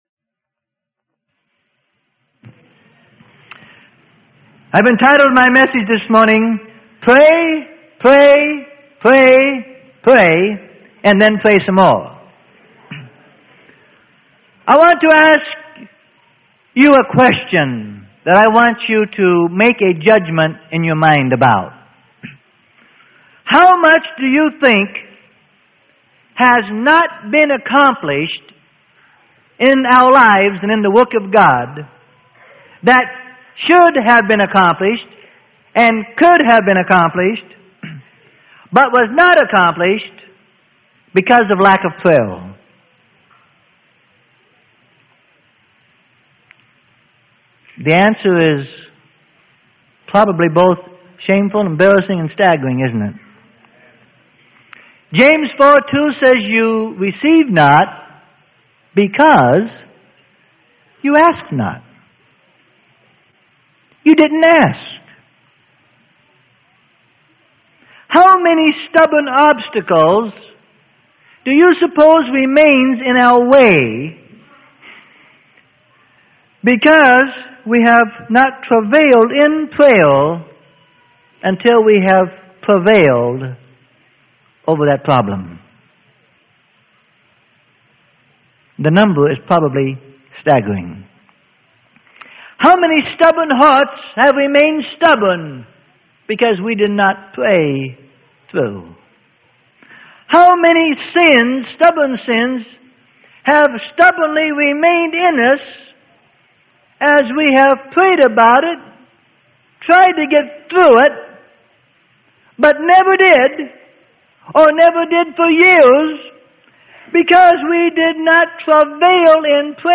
Sermon: Pray Pray Pray Pray and Then Pray Some More - Freely Given Online Library